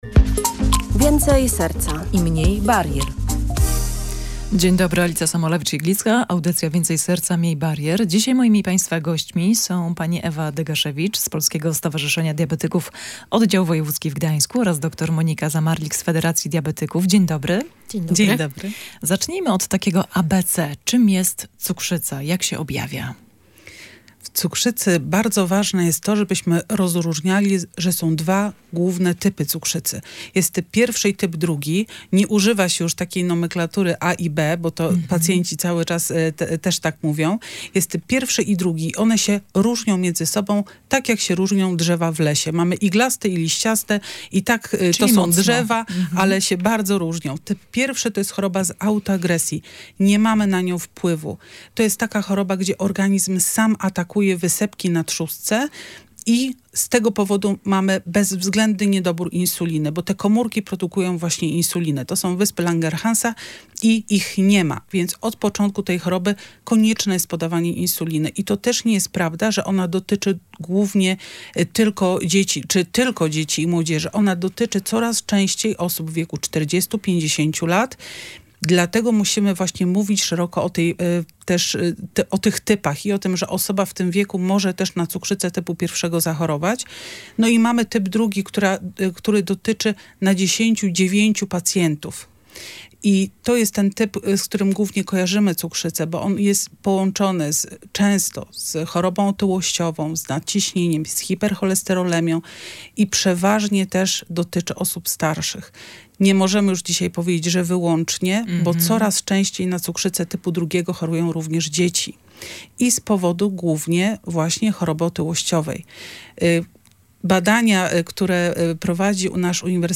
Cukrzyca dziś: rozmowa o leczeniu, życiu z diagnozą i znaczeniu profilaktyki